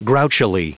Prononciation du mot grouchily en anglais (fichier audio)
Prononciation du mot : grouchily
grouchily.wav